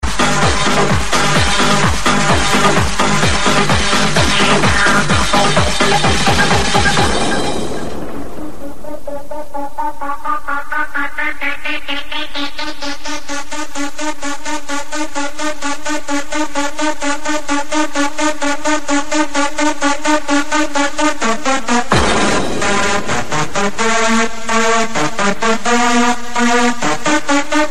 Club hit from late 90's or early 2000's??